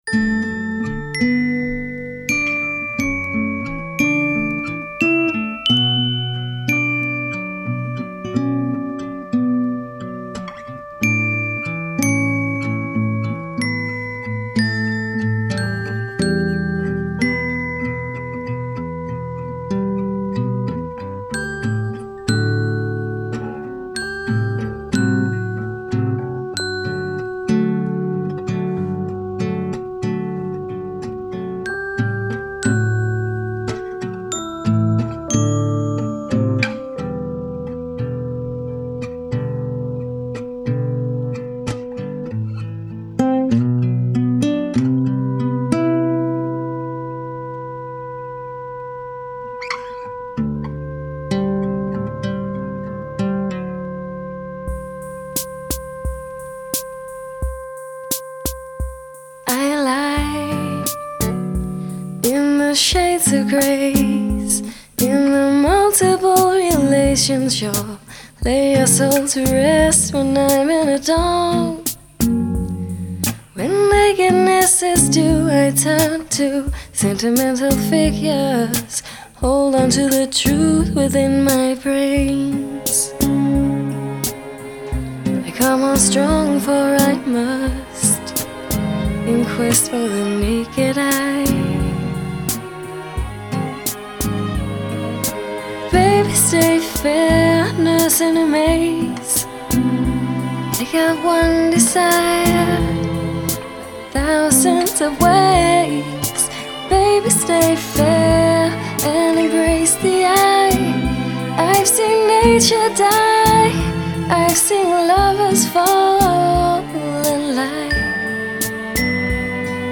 Genre: Downtempo, House, Future Jazz.